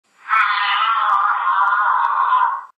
Giraffe 6 Sound Effect Free Download
Giraffe 6